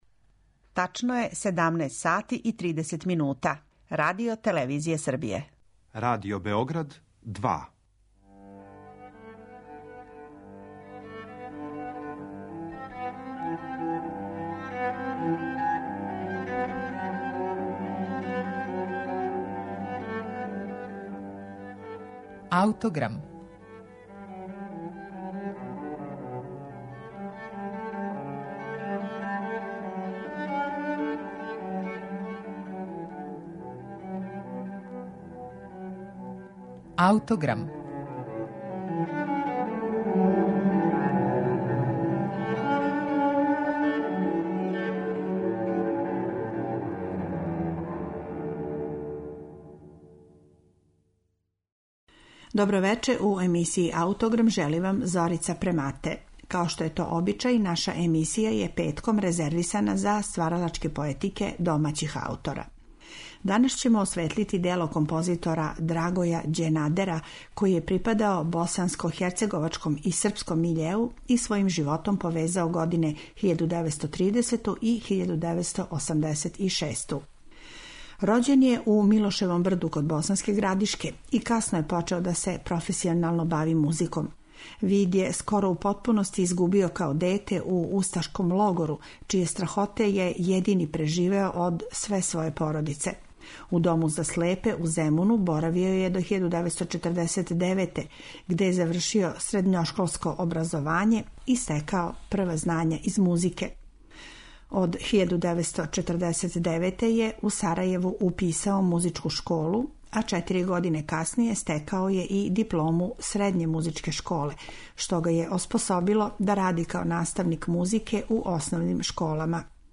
Деловао је средином прошлог века углавном у Сарајеву, а представиће га, између осталог, његова Прва симфонија.